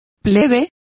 Also find out how plebe is pronounced correctly.